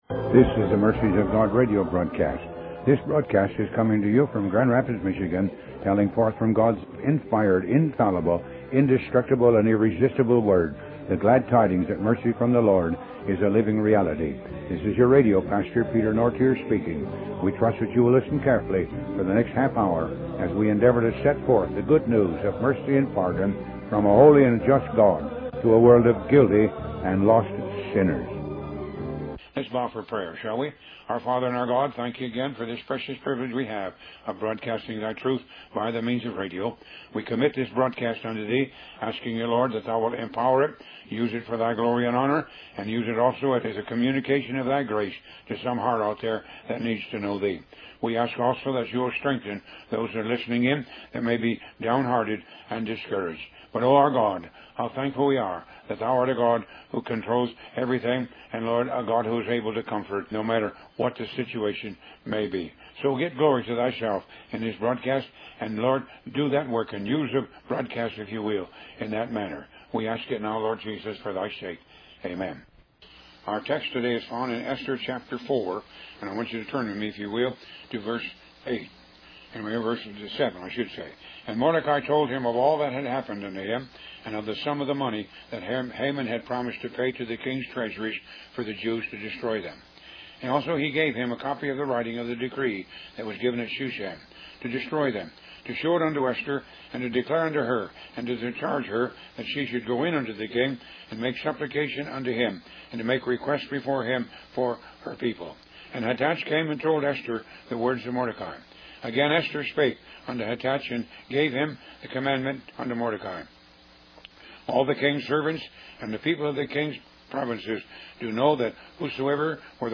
Upon his passing, the Ministry has continued the radio broadcast on some radio stations and through various social media sites.